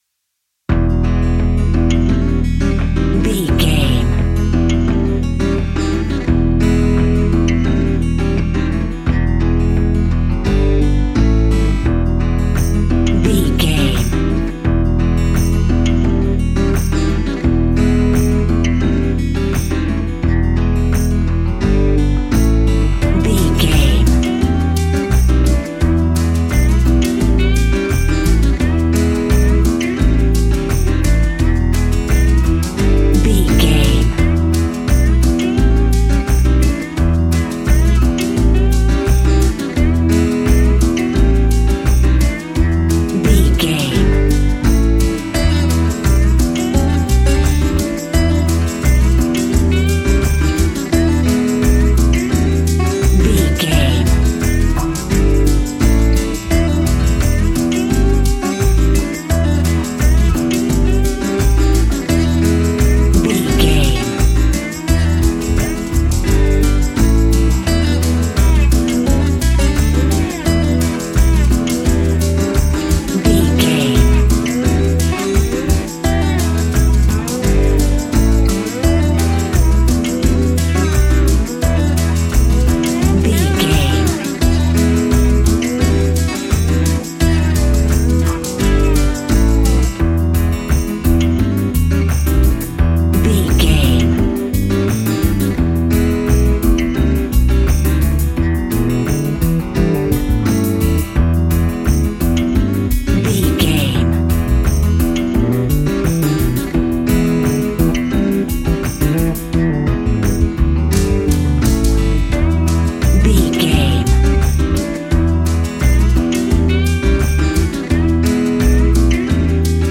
Aeolian/Minor
romantic
sweet
happy
acoustic guitar
bass guitar
drums